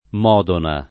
Modona [ m 0 dona ] → Modena